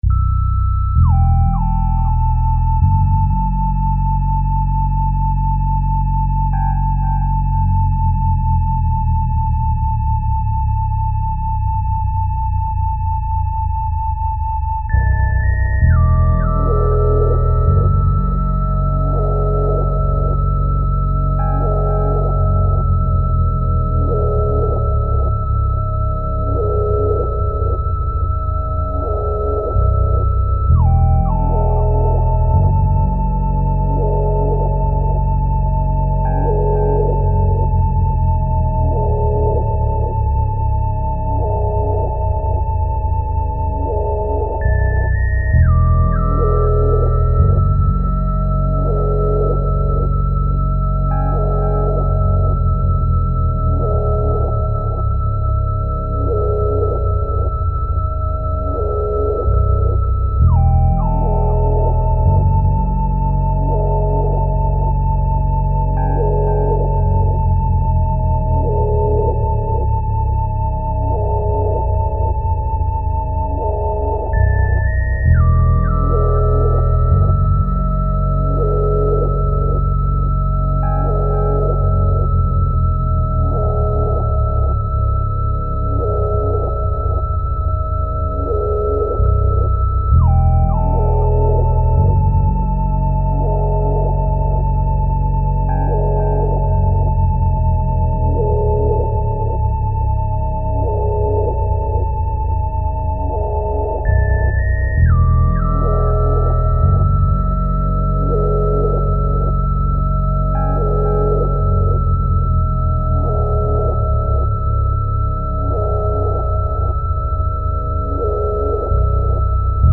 File under: Avantgarde
shifting around beats and rhythmic textures